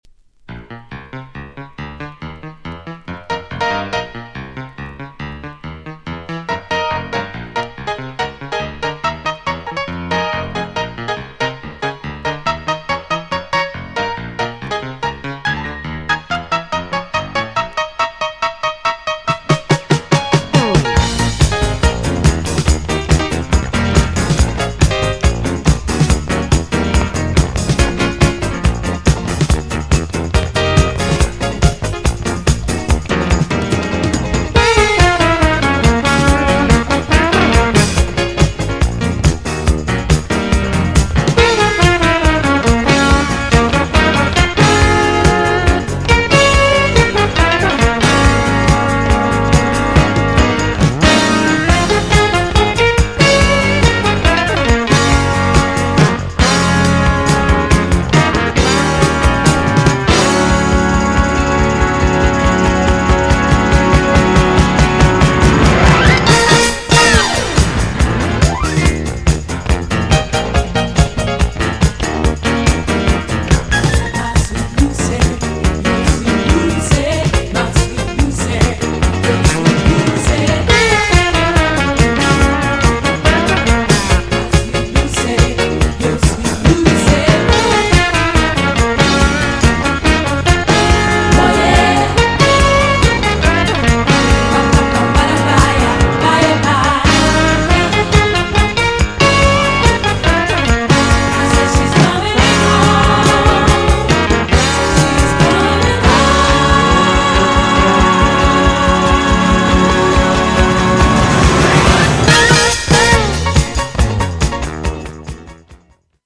B面は最後までアゲっぱなしで盛り上がり確実!!
GENRE Dance Classic
BPM 121〜125BPM
# FUSION
# INSTRUMENTAL # JAZZY
# アップリフティング # ブラジリアン # ラテン